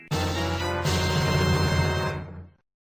Texture-Pack/assets/minecraft/sounds/block/chest/open.ogg at master
spatial sound ... Son en 3D O_o 2024-01-01 14:23:56 +01:00 17 KiB Raw Permalink History